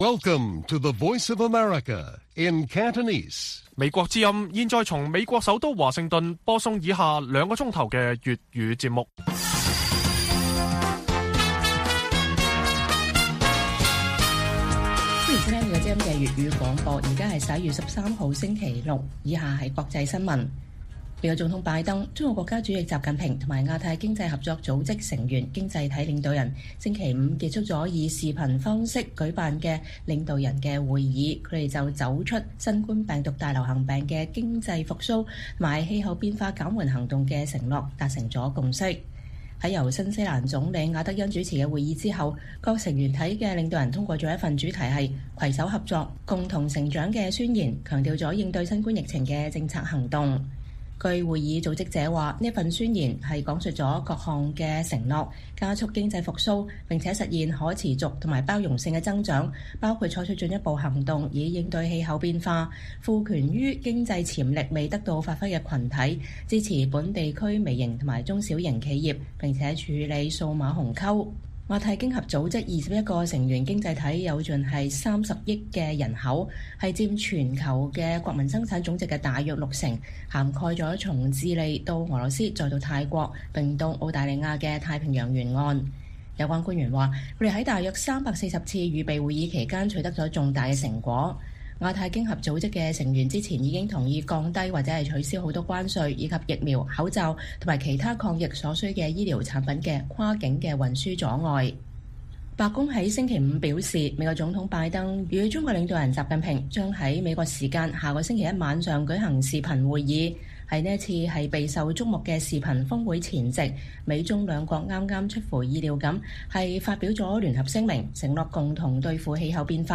粵語新聞 晚上9-10點：白宮：拜習會是為更有效與中國競爭設置規範